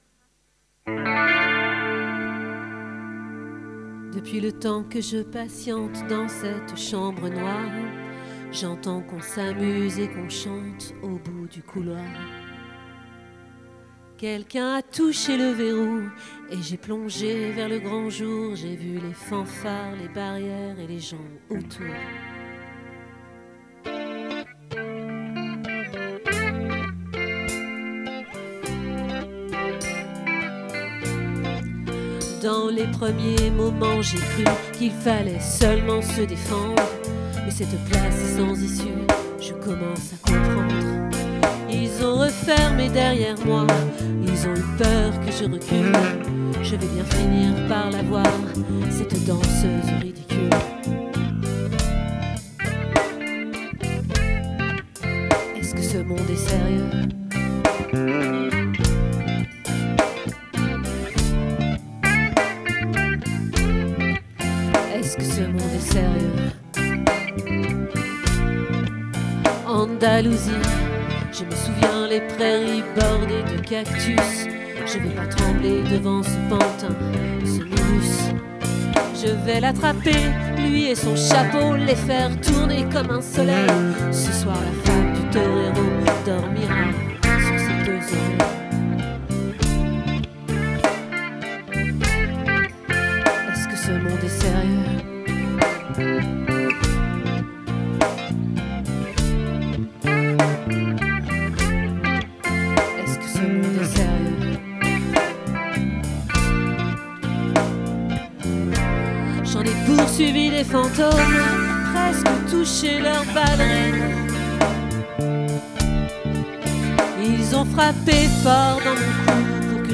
🏠 Accueil Repetitions Records_2025_02_03